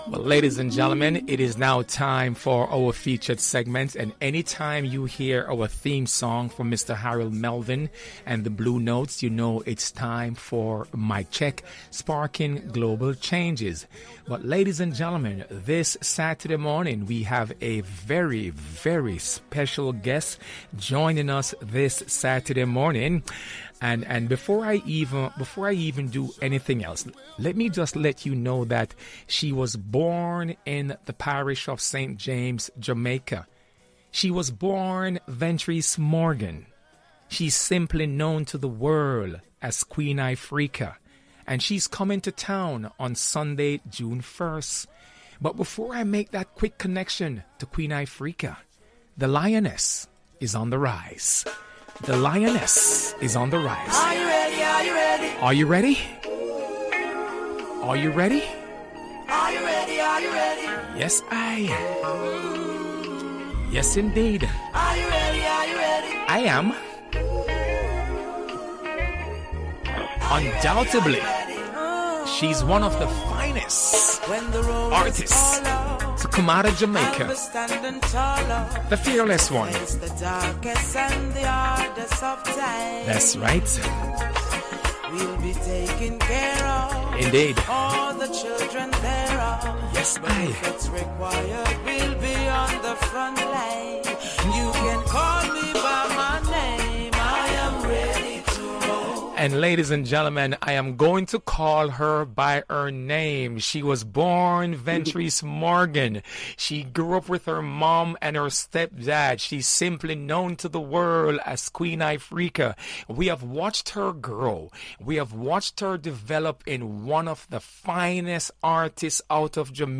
Over The Years We Have Watched Her Develop Into One Of The Most Powerful Voice In Reggae Music A True Cultural Awareness Singer Sing Jay Songwriter A One & One Sit Down Conversation With Queen Ifrica